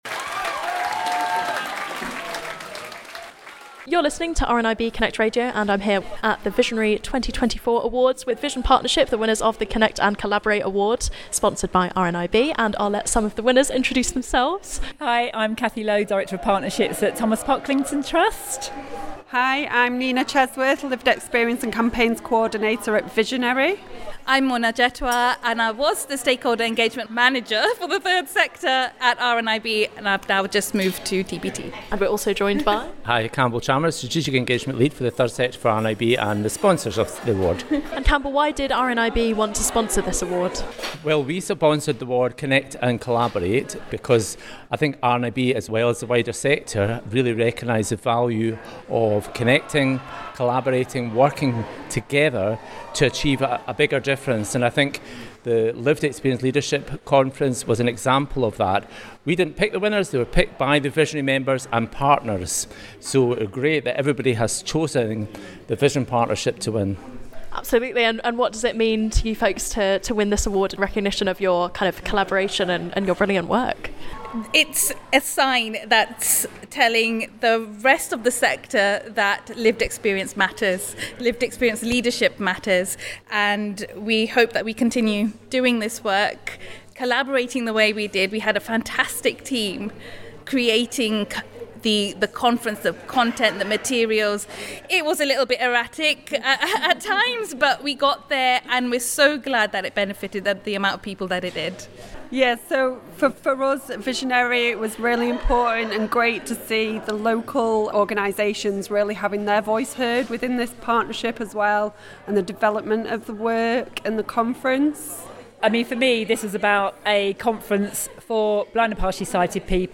RNIB sponsored the Connect and Collaborate Award and spoke to the Vision Partnership right after their win.